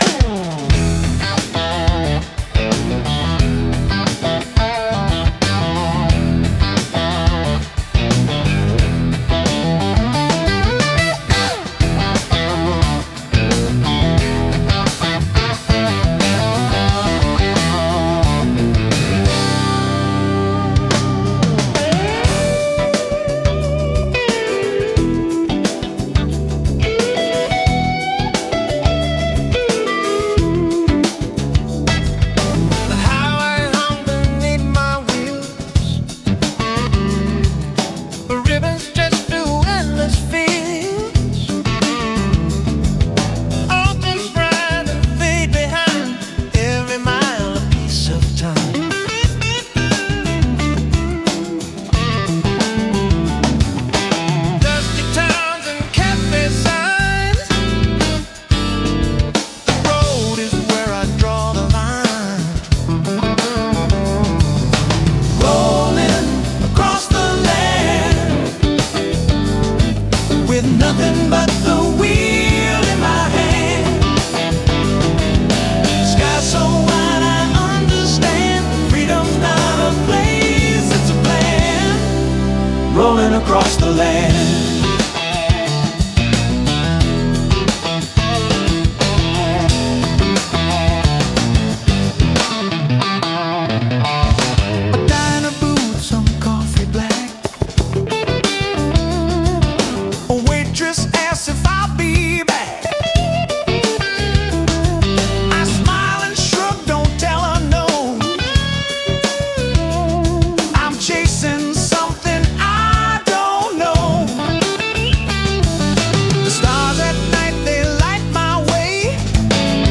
Rhythm and Blues